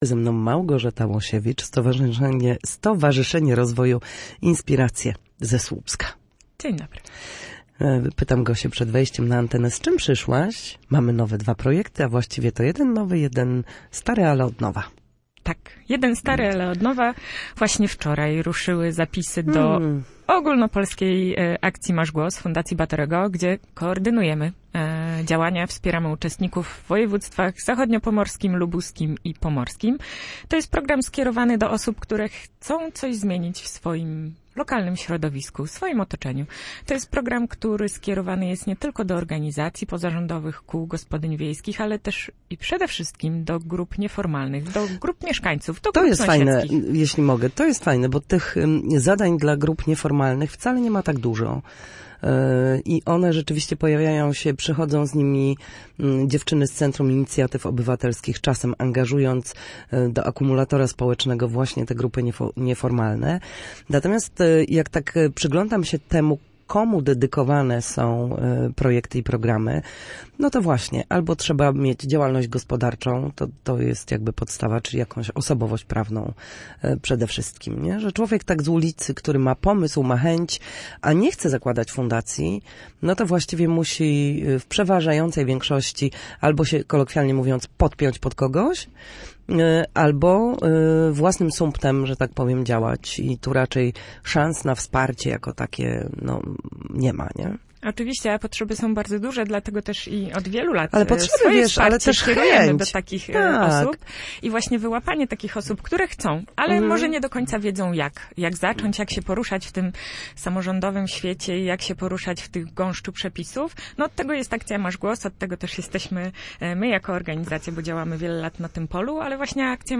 Na naszej antenie mówiła o najbliższych działaniach stowarzyszenia oraz projektach, na które warto zwrócić uwagę.